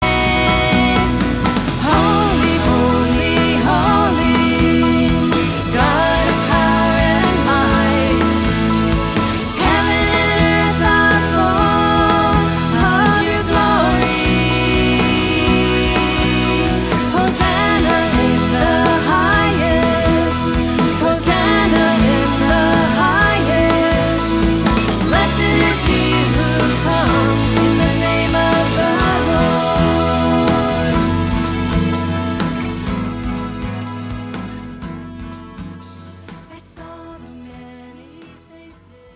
Contemporary and liturgical worship and service music